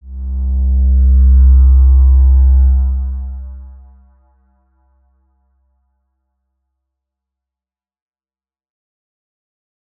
X_Windwistle-C1-ff.wav